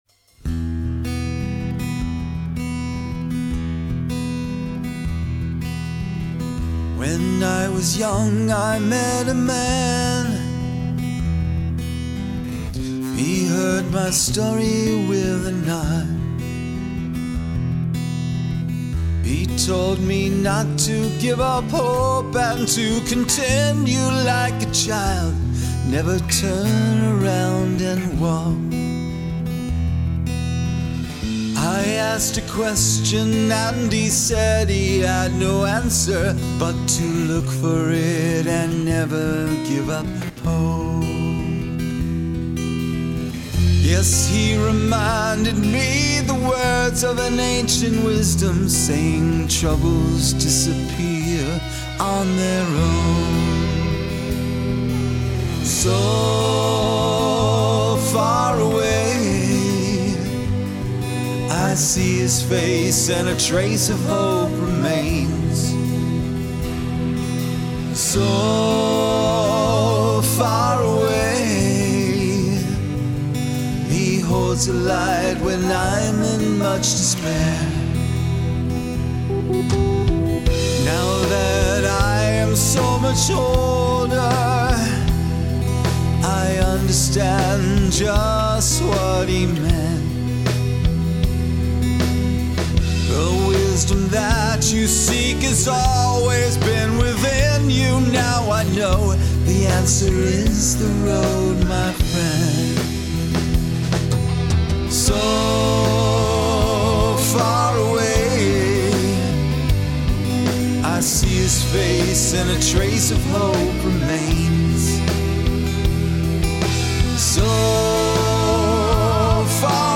Guitars
Vocals
Keyboards
Drums